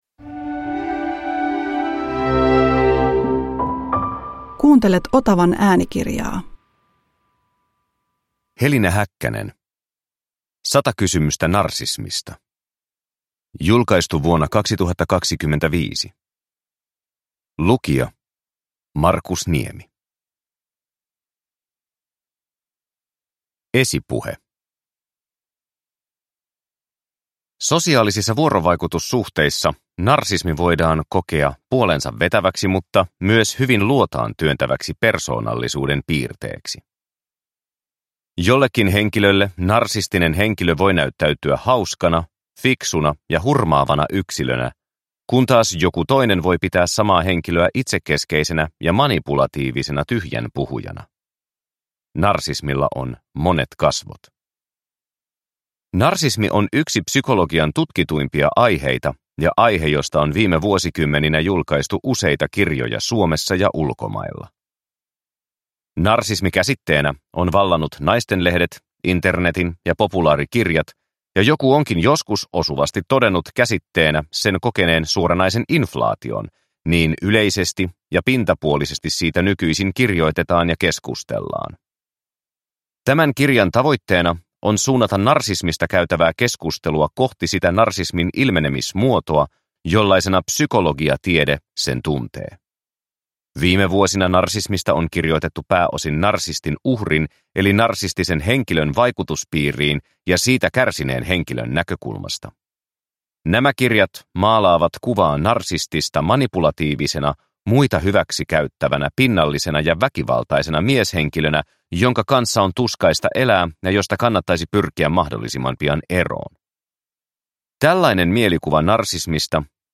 100 kysymystä narsismista – Ljudbok